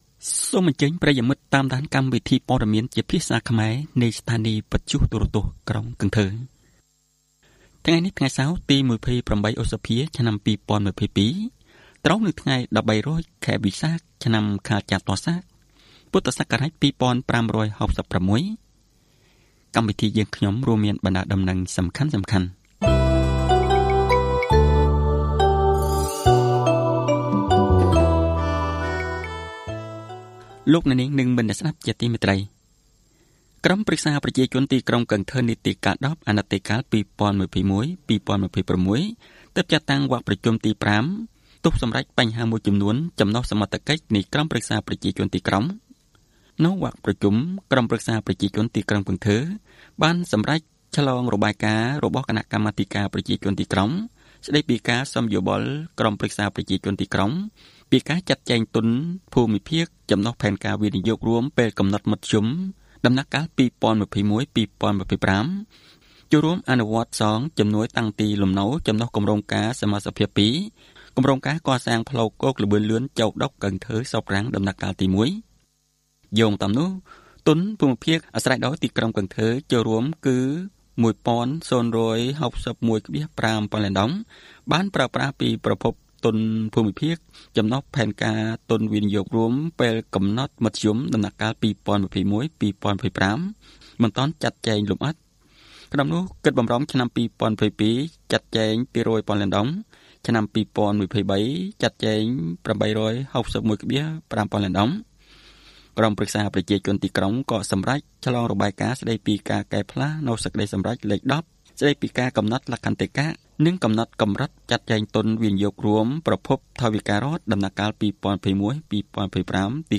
Bản tin tiếng Khmer sáng 28/5/2022